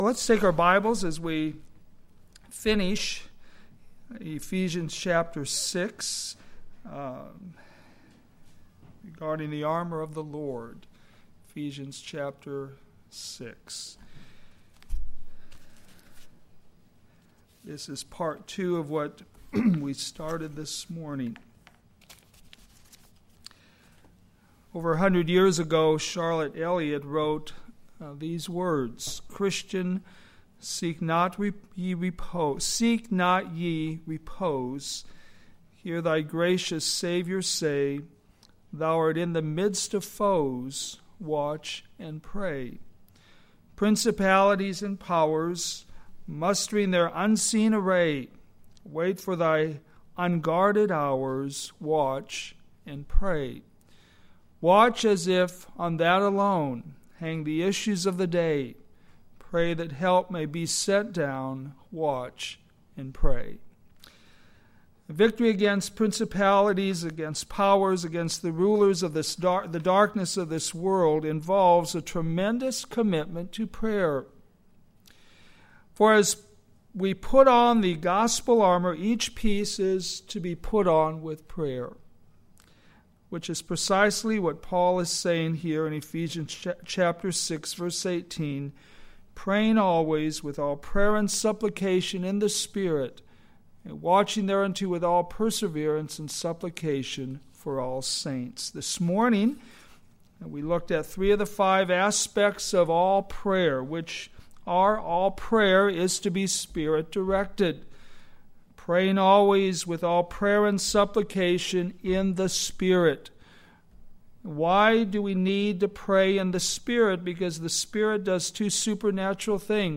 Sermons based on New Testament Scripture